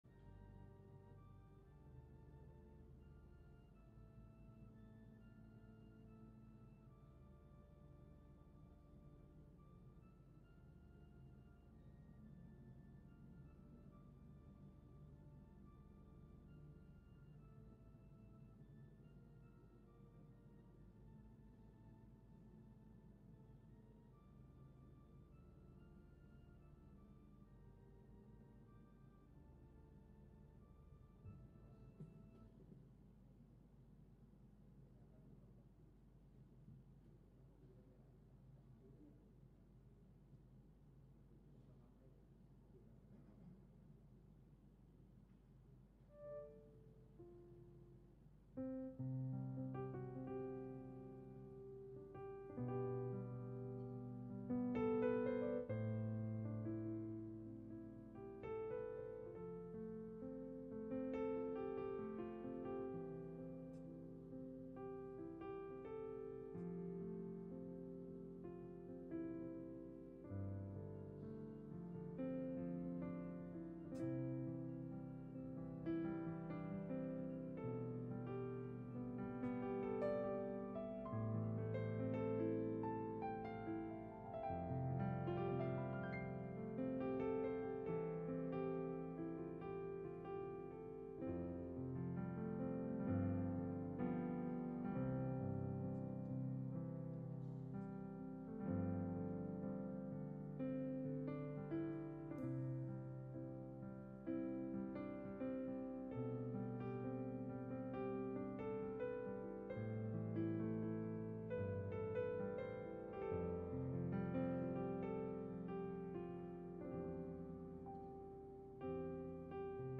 Kasihilah Tuhan Allahmu – Ibadah Minggu (Pagi 1)